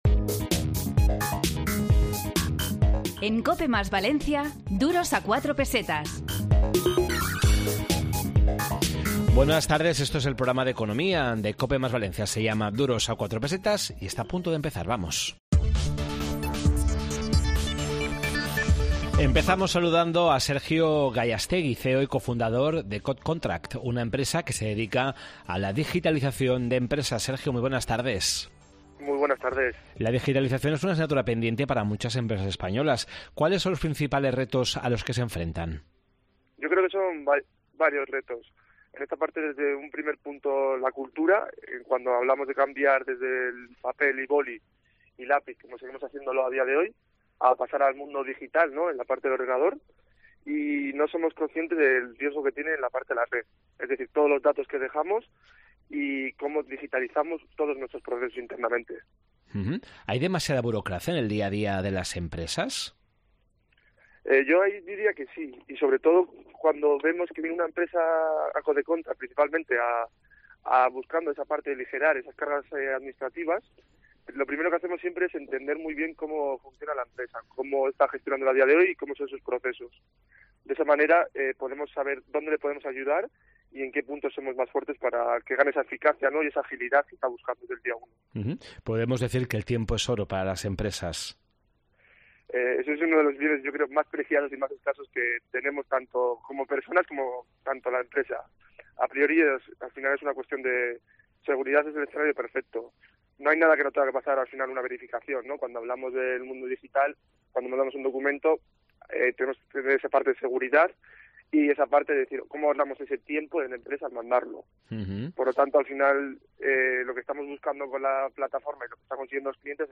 Esta semana, en Duros a 4 Pesetas de COPE Más Valencia, en el 92.0 de la FM, hemos hablado sobre la digitalización de tareas burocráticas, los retos de las empresas durante la COVID-19 y el mercado automovilístico.